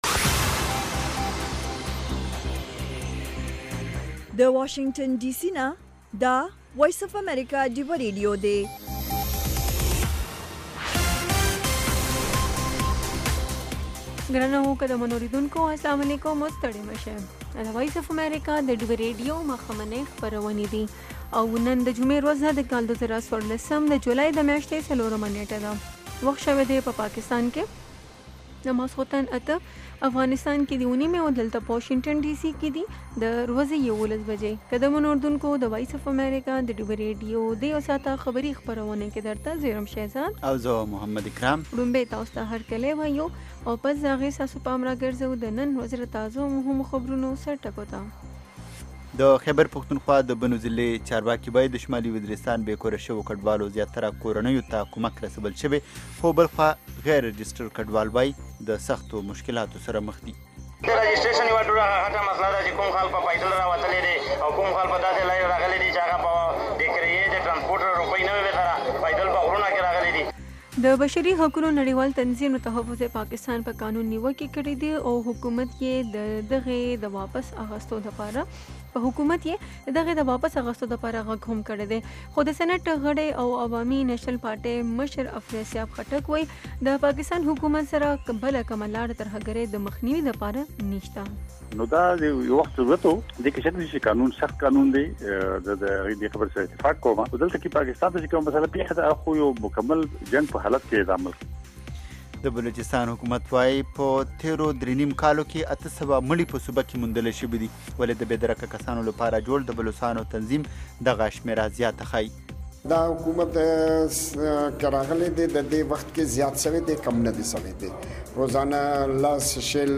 خبرونه - 1500